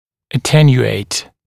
[ə’tenjueɪt][э’тэнйуэйт]ослаблять, смягчать